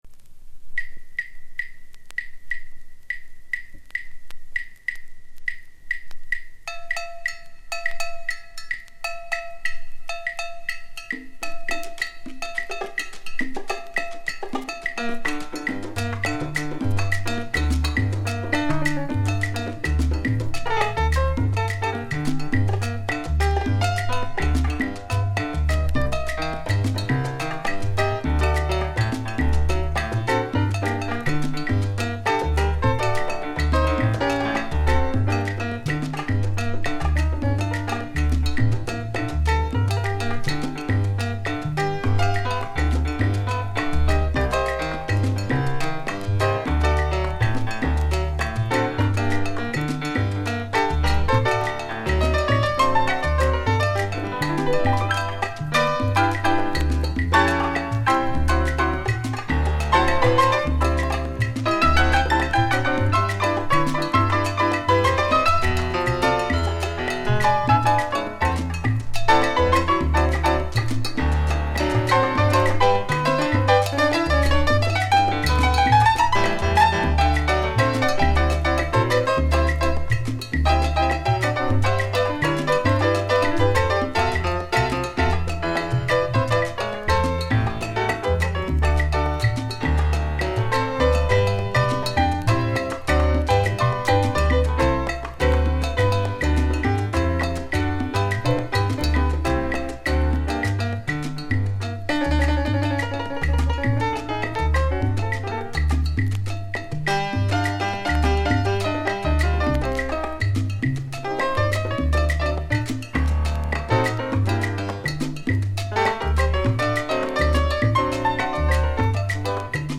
１９３０年代から活躍するキューバの超名門チャランガ・オルケスタの作品 !!!
ベースが太く